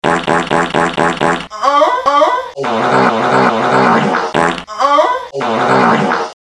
Dummy Hard Wet Fart - Bouton d'effet sonore